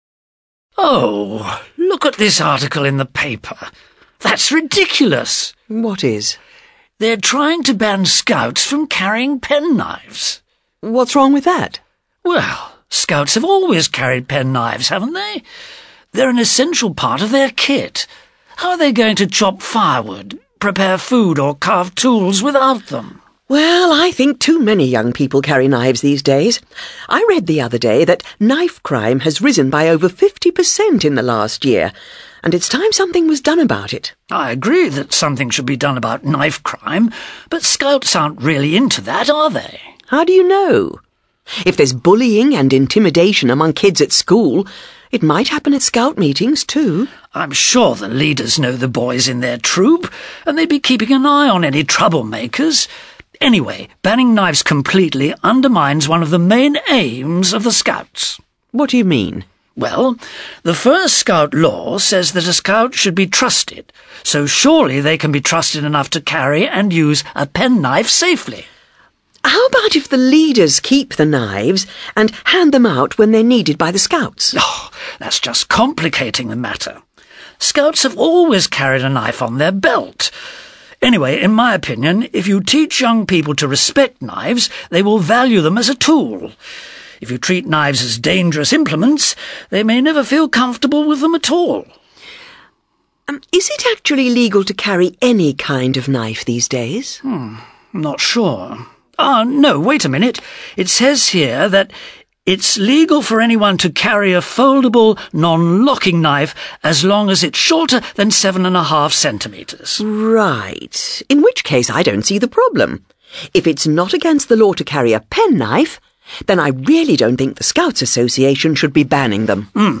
ACTIVITY 27: You are going to listen to a conversation between two people about a newspaper article.